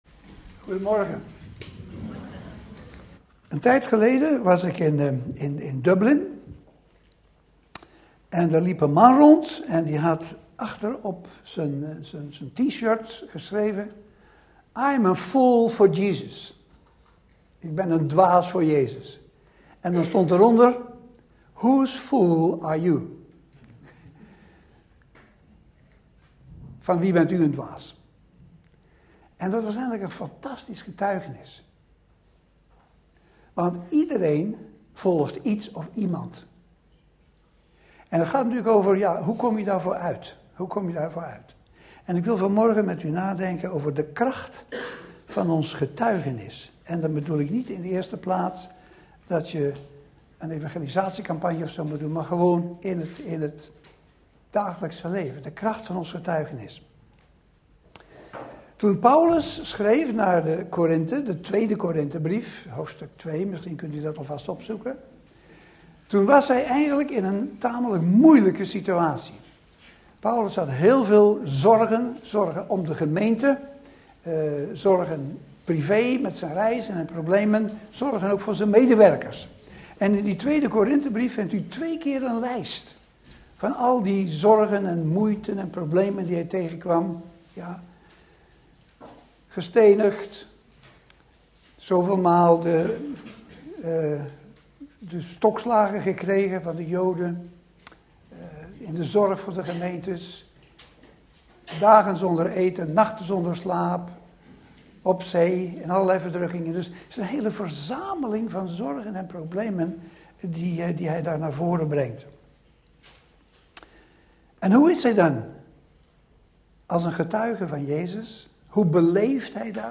In de preek aangehaalde bijbelteksten